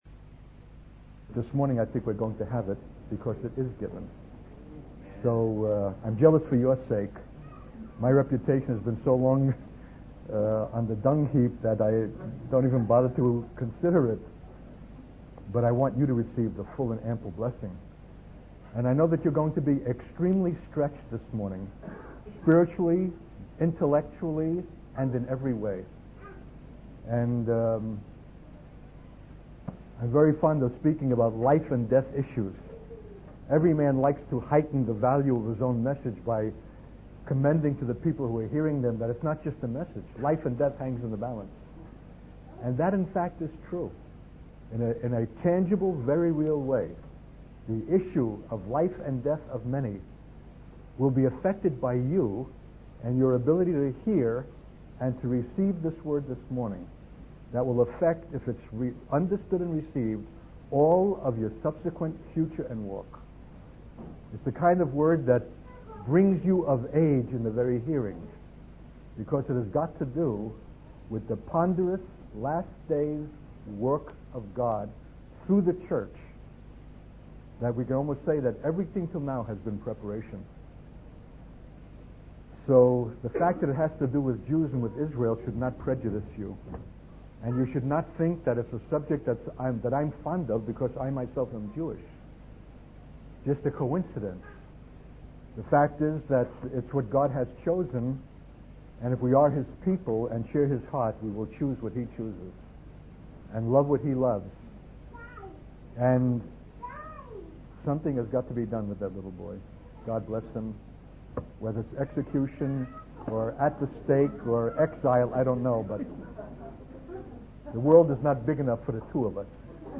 In this sermon, the speaker emphasizes the importance of God's people reaching out to the Jewish people during a time of trouble and affliction. He warns that if the Jews are not taken in and protected, they could face the same fate as they did in Nazi Germany. The speaker also discusses the resurrection of an entire nation, which he believes will be brought about by a prophet who will speak to the dry bones of that nation.